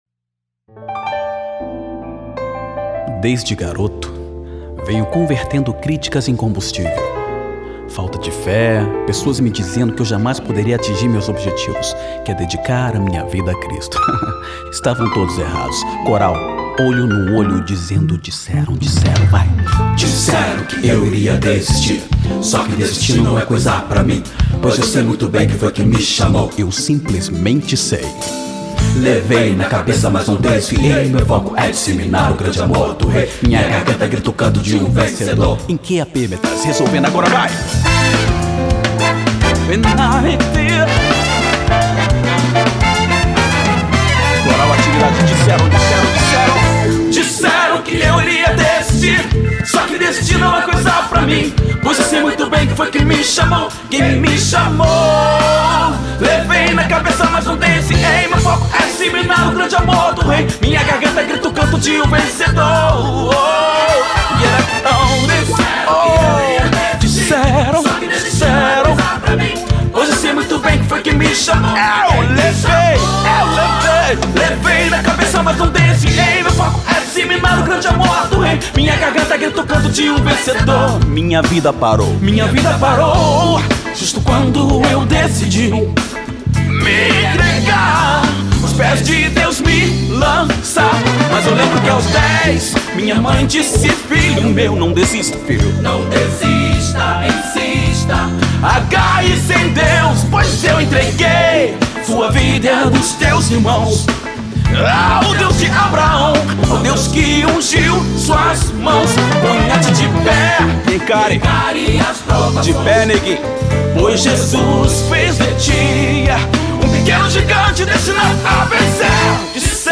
versão editada para o rádio